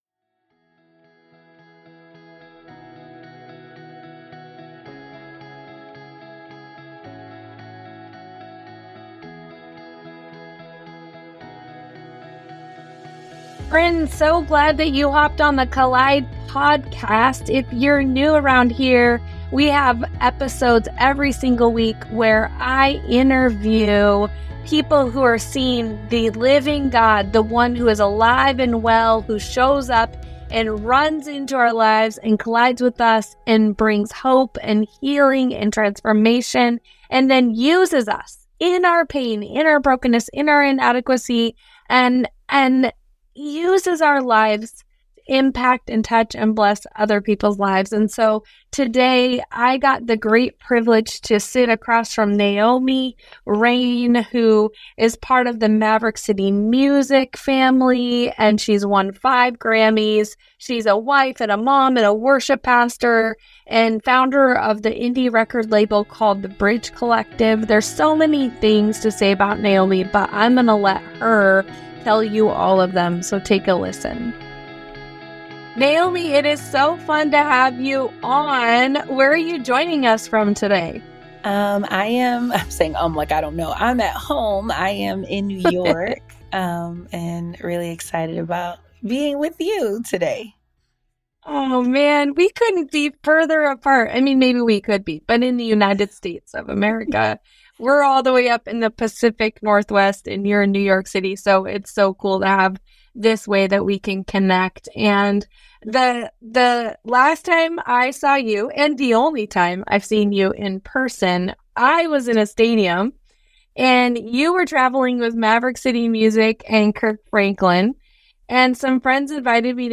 In this episode of The Collide Podcast, we sit down with 5x GRAMMY Award-winning artist Naomi Raine to talk about faith, music, calling, and character. Naomi shares the heart behind her worship, her journey from childhood dreams to Maverick City Music, and what it means to stay anchored in God amid public platforms and personal challenges.